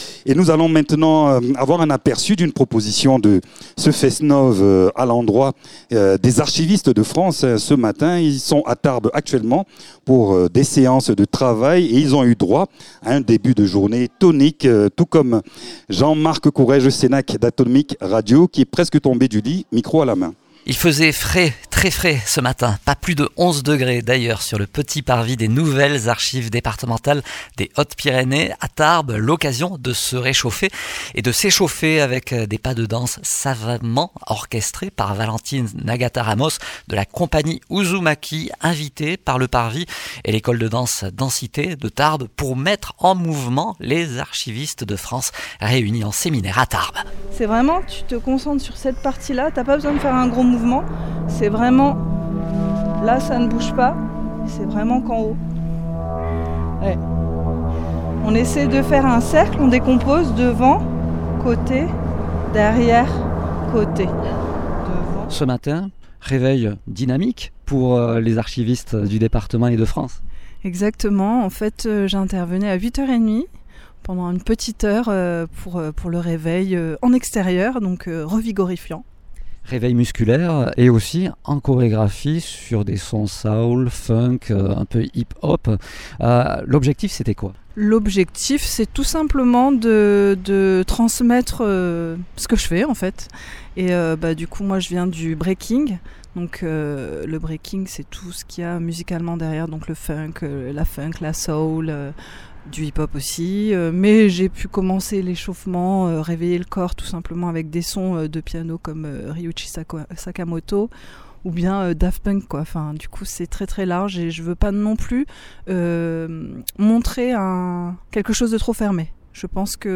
Lors d’un séminaire à Tarbes, des archivistes venus de toute la France ont débuté leur journée par un atelier de danse matinal.